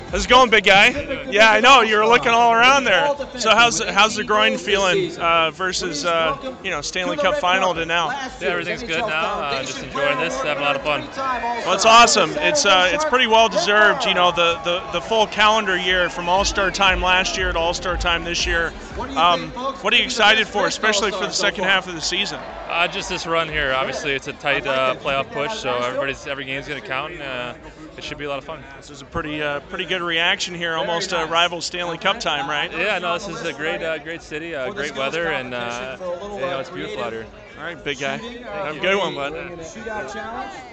at the 2016 NHL All-Star Game.